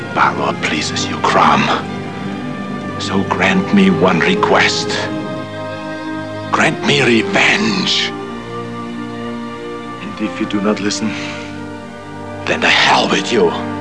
"Grant me REVENGE!" Conan prays